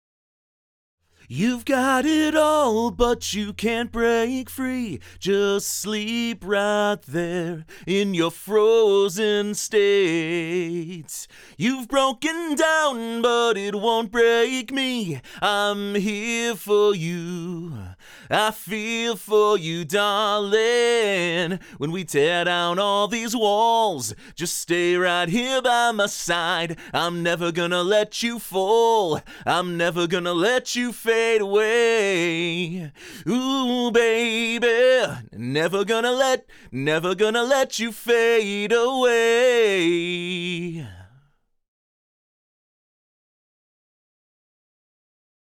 The vocals are recorded with a Golden Age GA-251 tube condenser microphone, going into an Apogee Symphony I/O preamp, and then sent over to a Golden Age Comp-2A optical compressor, which is applying around 2-3 dB of reduction to control the dynamics.
Here are the same three examples from earlier, with the music muted, and the vocal isolated. The difference in tonal character and artifacts introduced are a bit more obvious in these examples.
04-Raw-Vocal-Solo_01.mp3